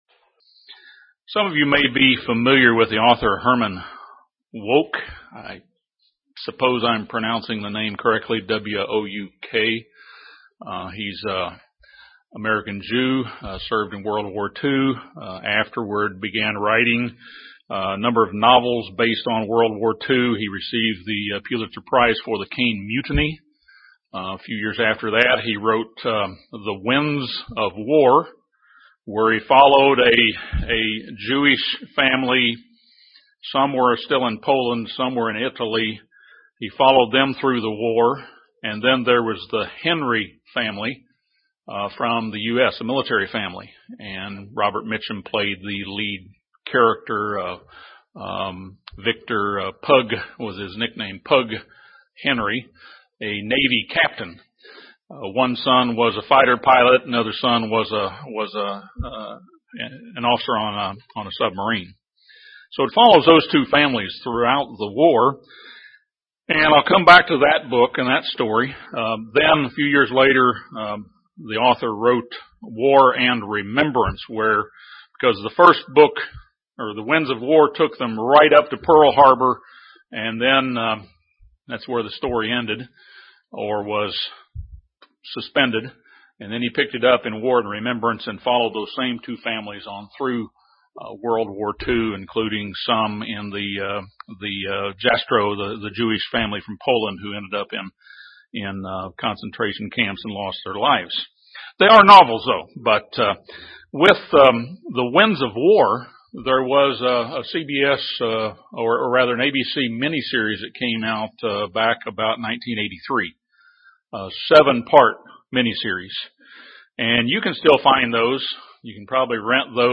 This sermon discusses events leading toward the end-time clash between the King of the North and King of the South.